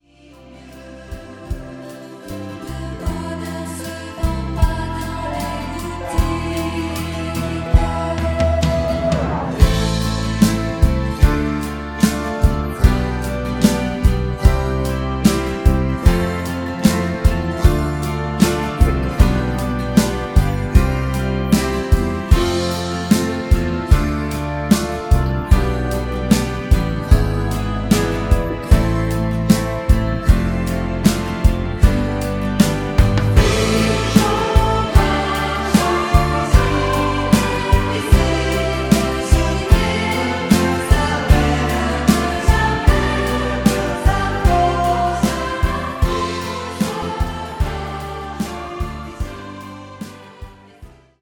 avec choeurs originaux